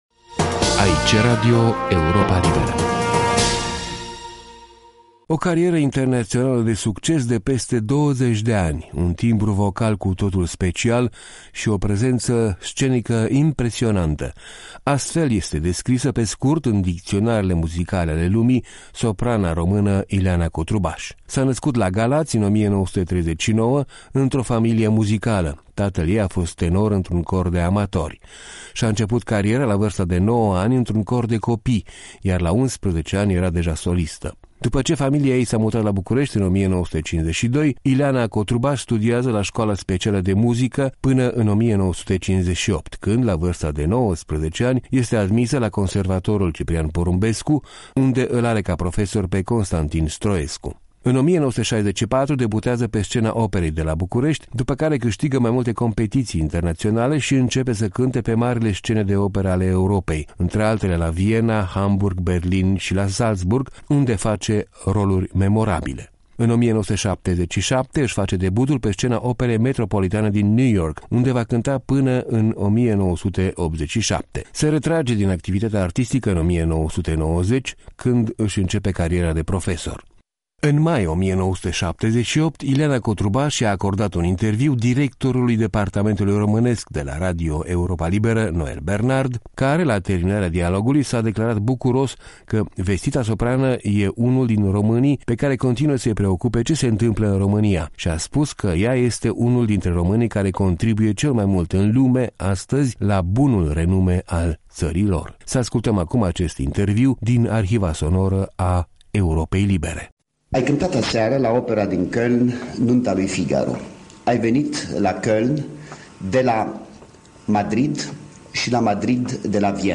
Un interviu realizat de Noel Bernard în mai 1978